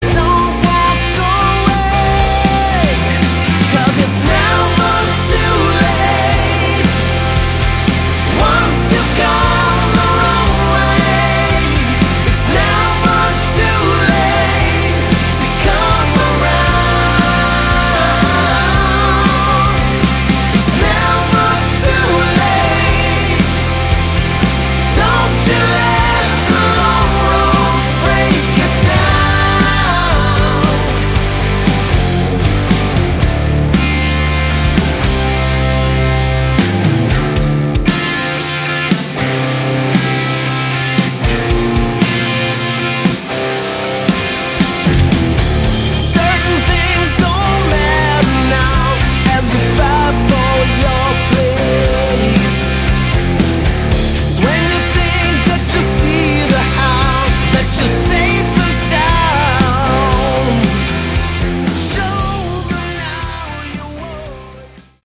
Category: Hard Rock
lead and backing vocals, bass, guitars, keyboards, drums
lead guitars, rhythm and acoustic guitars